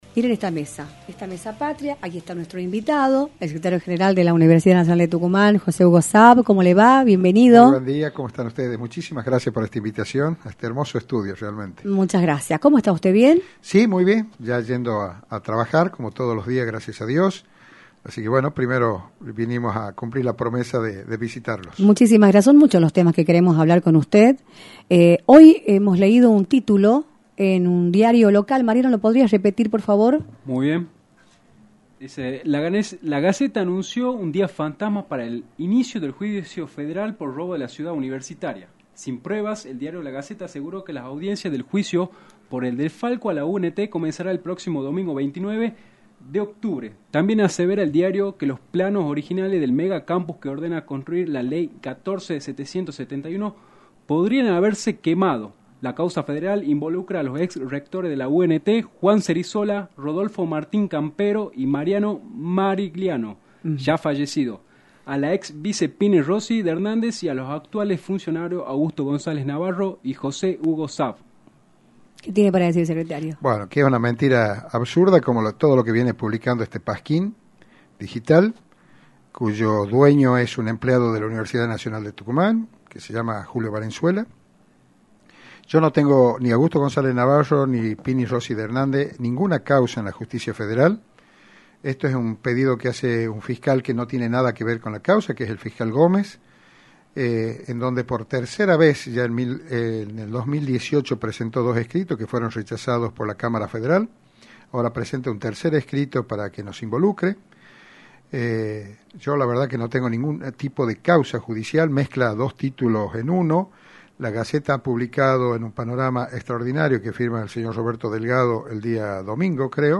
visitó los estudios de «Libertad de Expresión», por la 106.9
entrevista